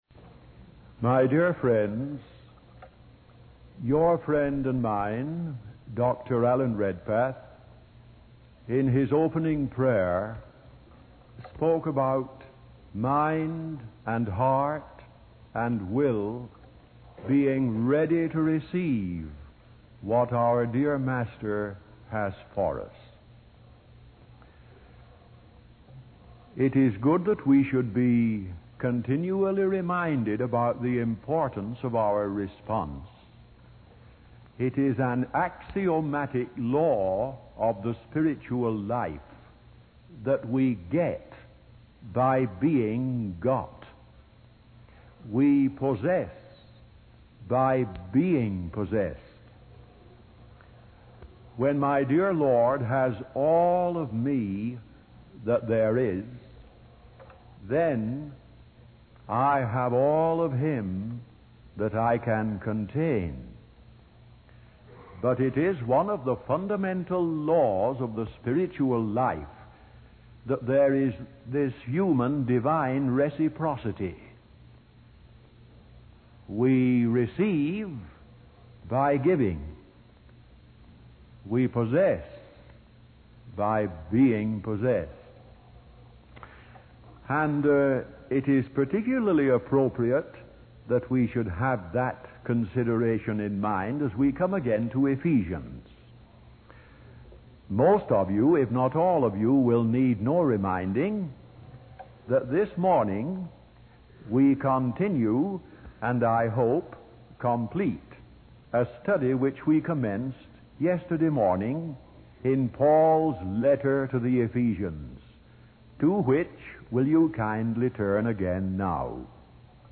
In this sermon, the speaker provides a recapitulation of the book of Ephesians, dividing it into two parts: chapters one to three being doctrinal and chapters four to six being practical.